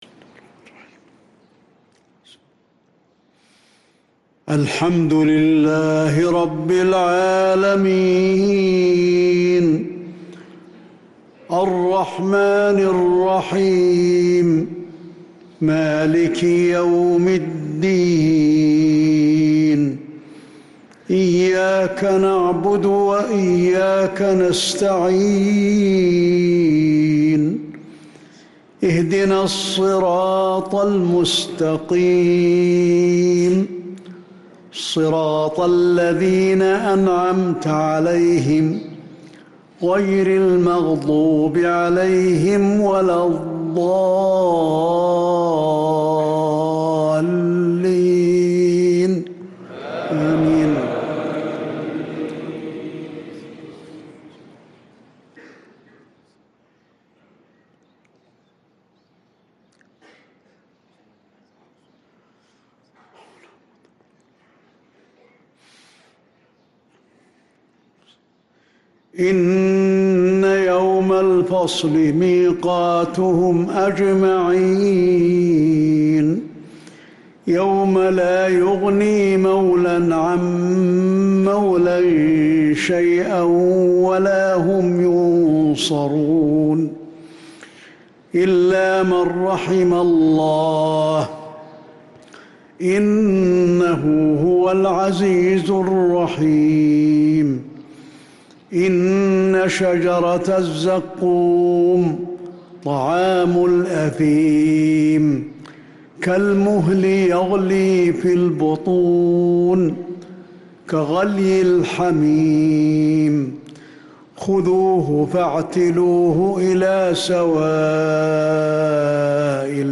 صلاة المغرب للقارئ علي الحذيفي 24 رجب 1445 هـ
تِلَاوَات الْحَرَمَيْن .